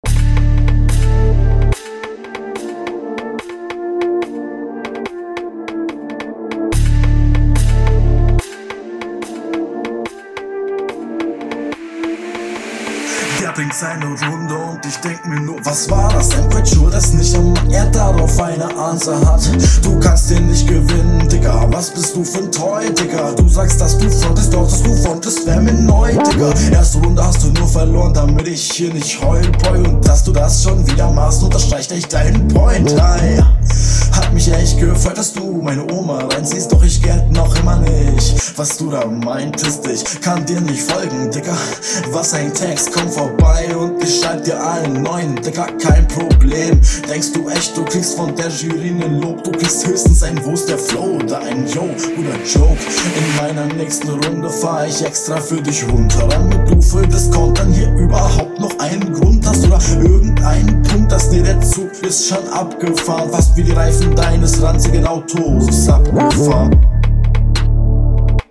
Du Hast du zu viel Hall auf deiner stimme kommst hier besser auf den Beat …